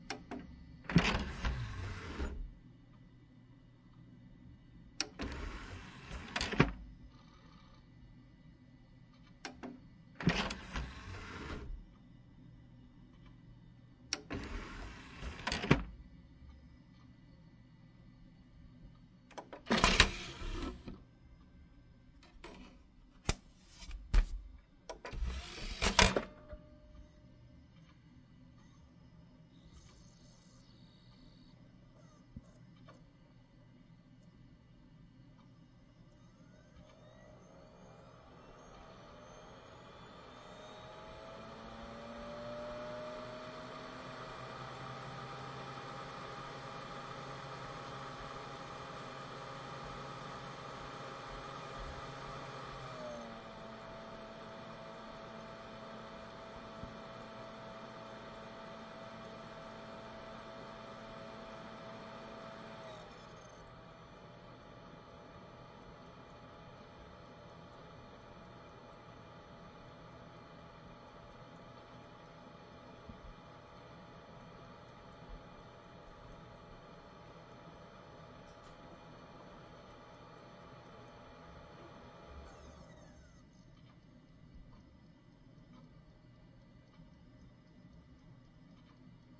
随机 " 电脑光盘驱动器打开关闭
Tag: CD 驱动 开放 计算机 关闭